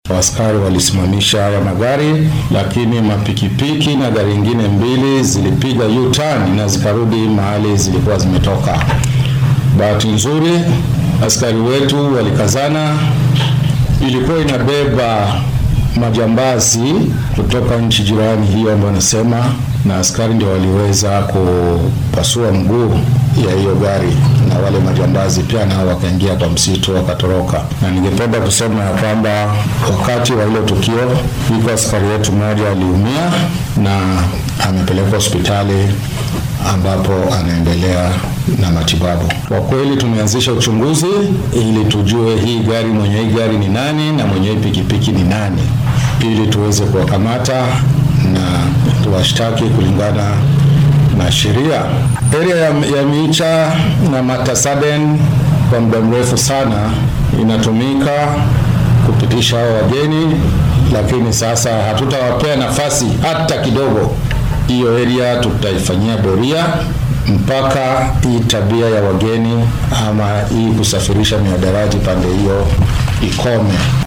Taliyaha booliiska ee ismaamulka Isiolo Xasan Barua ayaa arrintan warbaahinta faahfaahin ka siiyay.